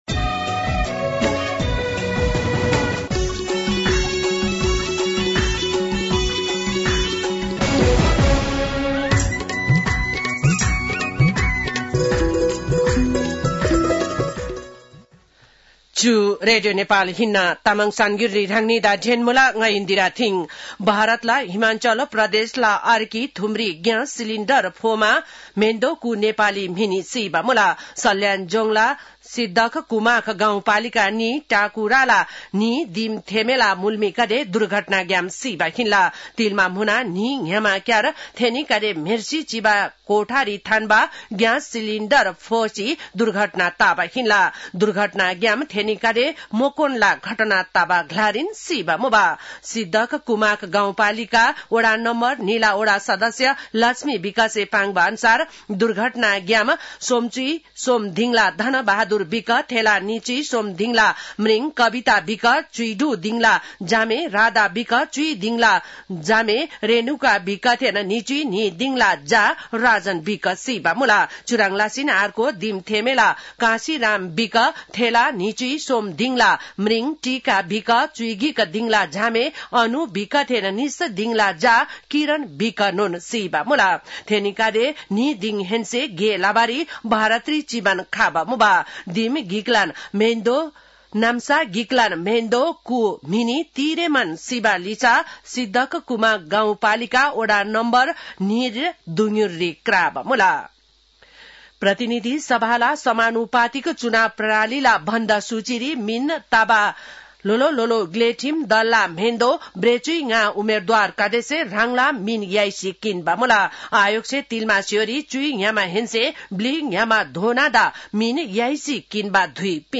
तामाङ भाषाको समाचार : २९ पुष , २०८२